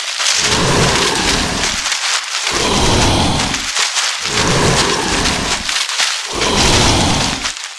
swamp_run_1.ogg